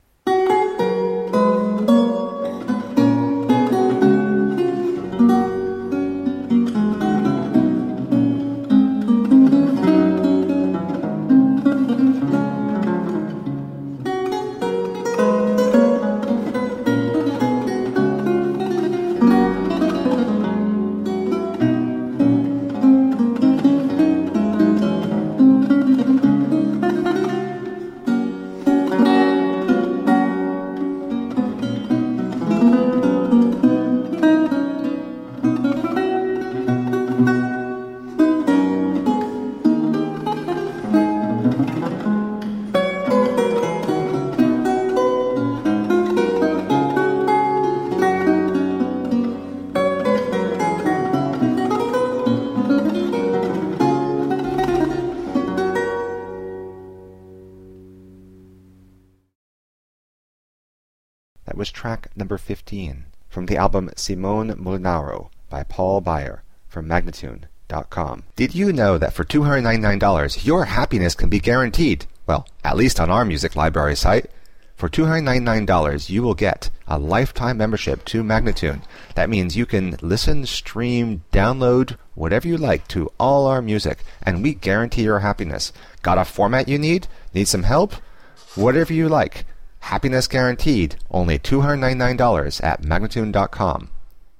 Solo lute of the italian renaissance..